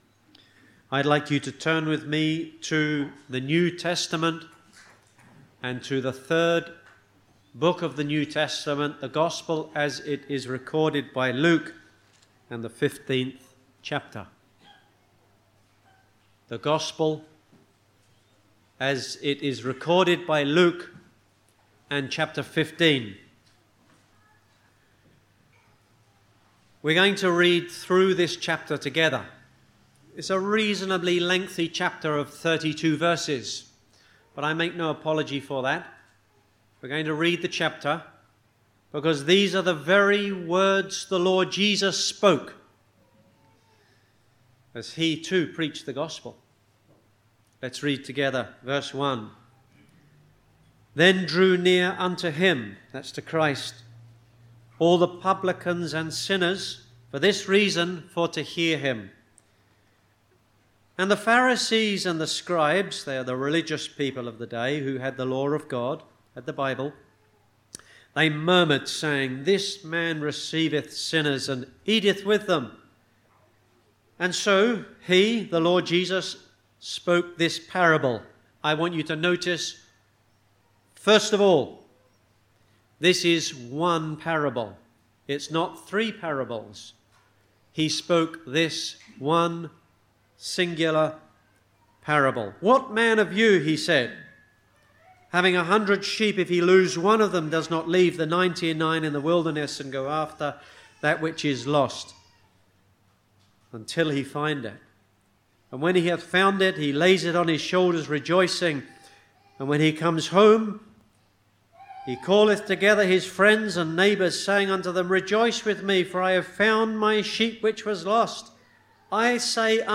Scripture reading: Luke 15 (whole chapter) Location: Cooroy Gospel Hall (Cooroy, QLD, Australia)